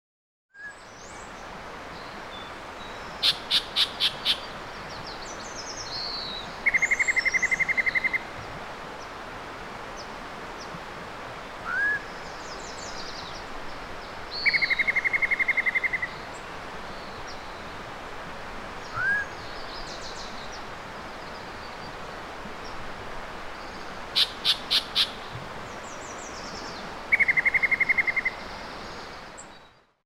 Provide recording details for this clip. Microphone: Rode NT1-A(x2) Recorder: Marantz PMD670